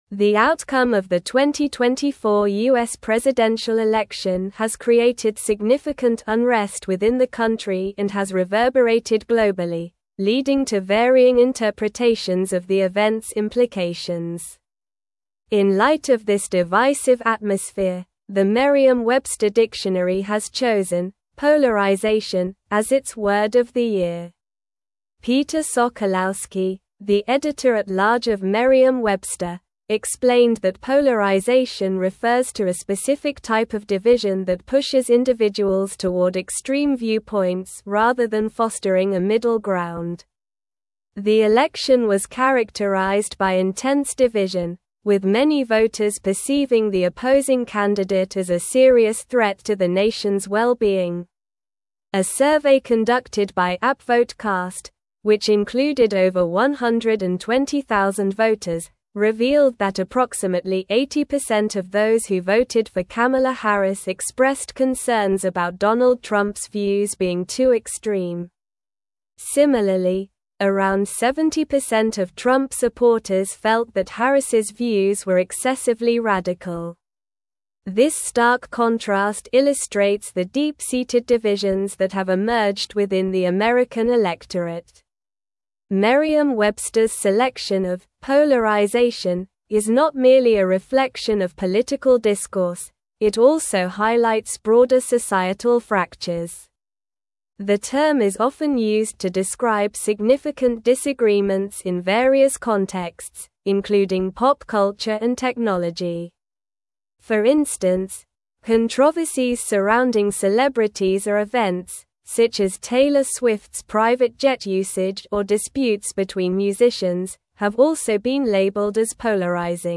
Slow
English-Newsroom-Advanced-SLOW-Reading-Polarization-Named-Merriam-Websters-Word-of-the-Year.mp3